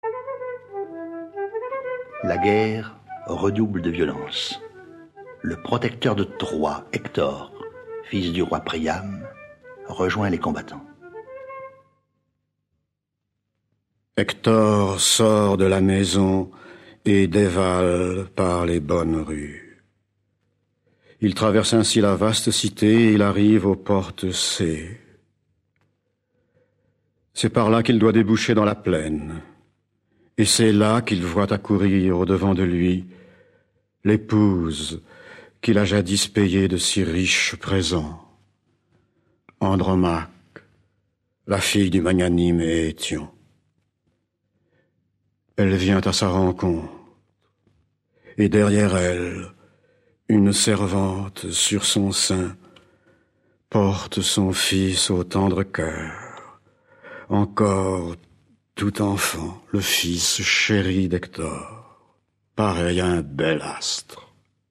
Diffusion distribution ebook et livre audio - Catalogue livres numériques
Durée 49 minutes Avec Alain Cuny, Michel Galabru, Anna Gaylor et 4 autres comédiens.